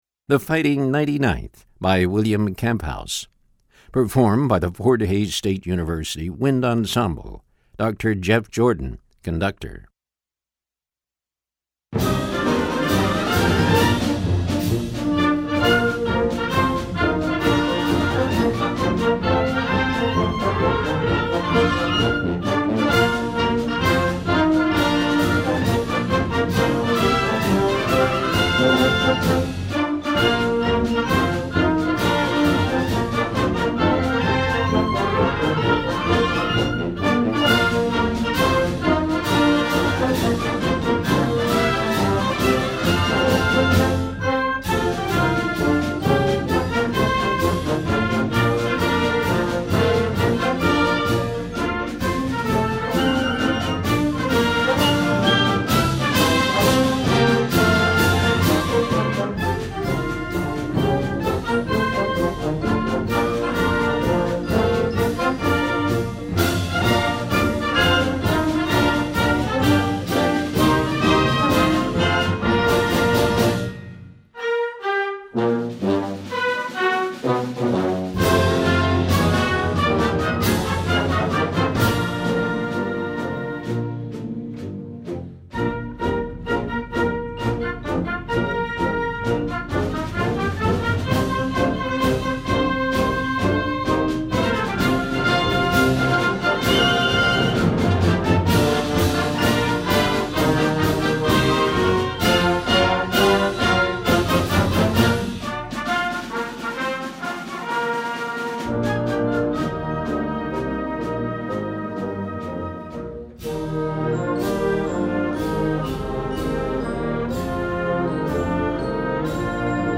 MUK 輸入吹奏楽行進曲（スコア＆パート）